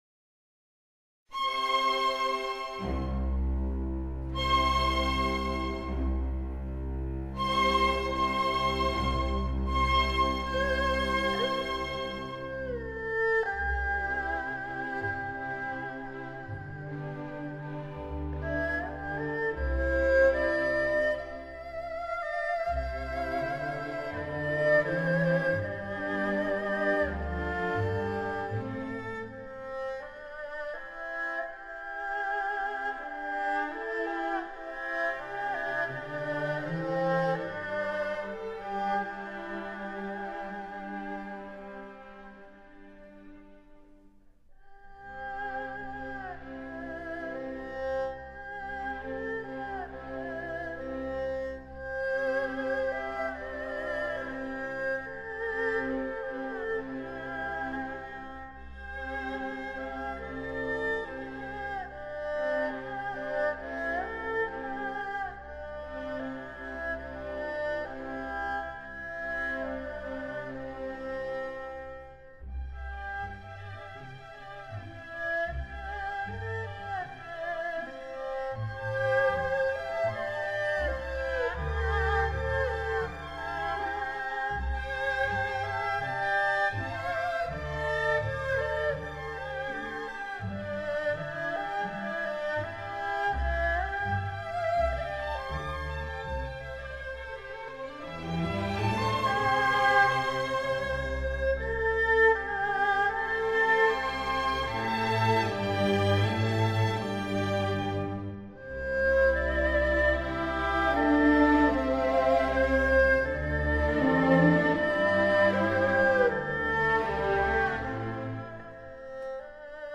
二胡與樂隊
中胡與樂隊
高胡、中胡與樂隊
二胡、中胡與樂隊